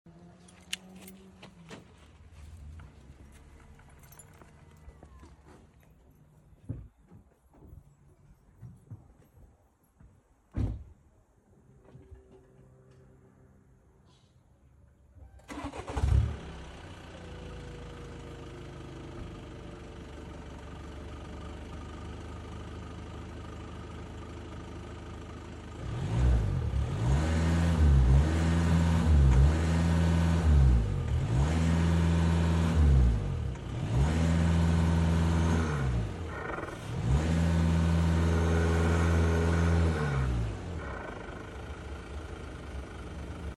VW GTD Sound With Back Sound Effects Free Download